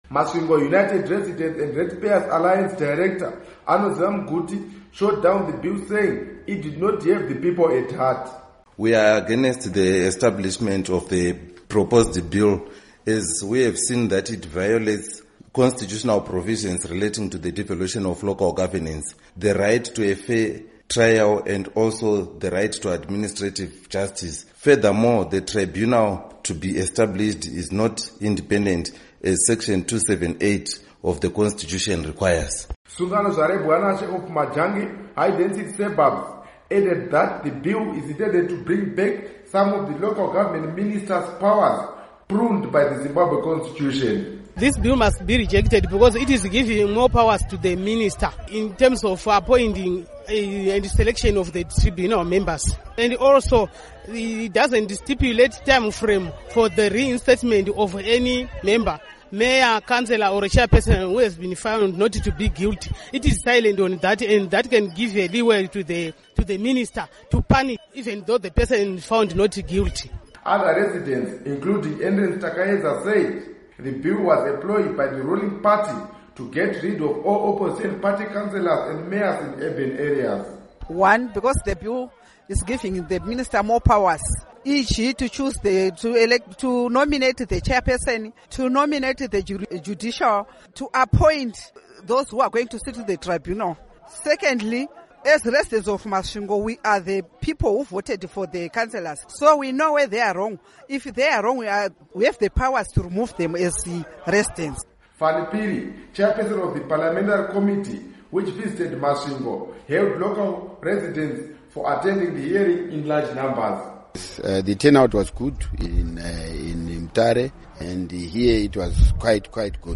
Report on Public Parly Hearings